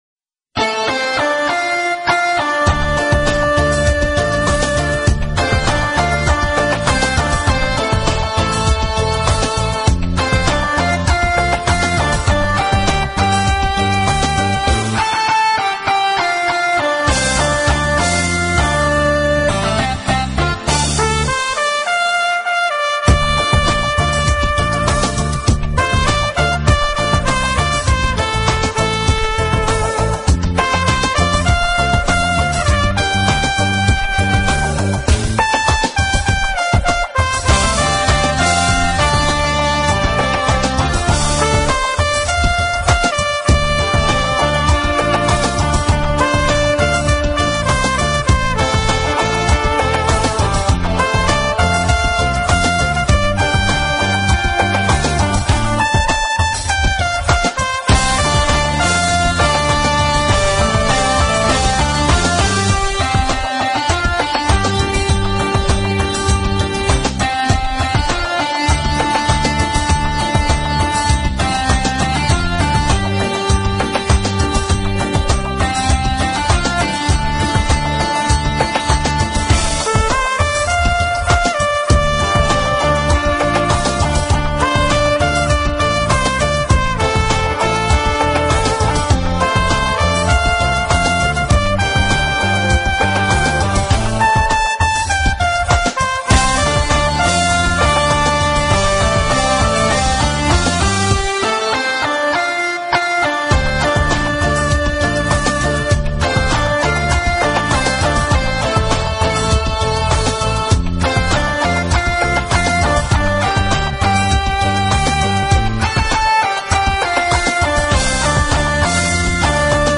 音乐类型：小号吹奏
高亢、嘹亮、耐人寻味的号角声一旦响起